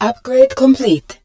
marine_upgradecomplete.wav